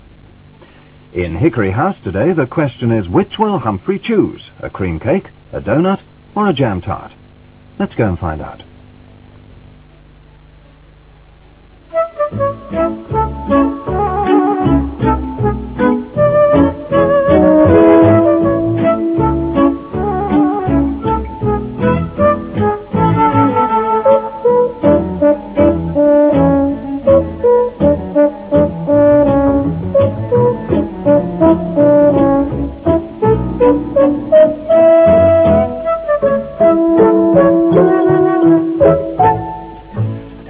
start music